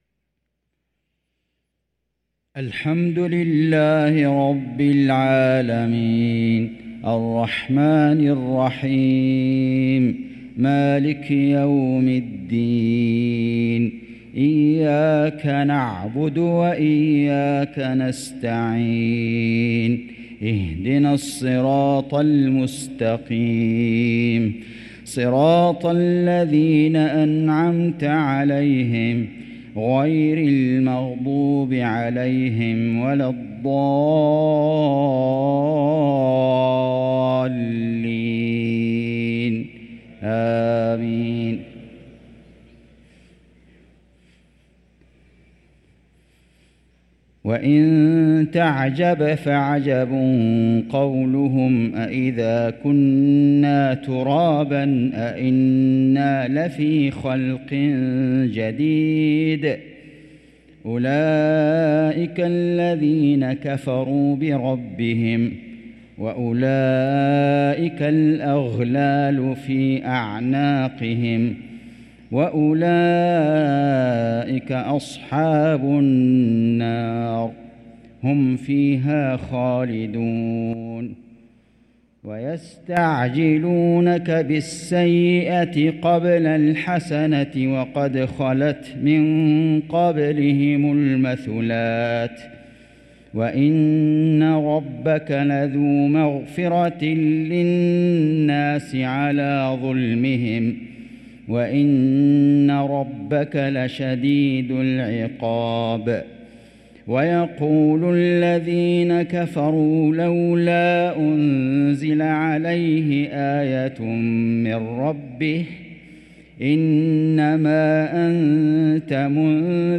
صلاة العشاء للقارئ فيصل غزاوي 5 جمادي الأول 1445 هـ
تِلَاوَات الْحَرَمَيْن .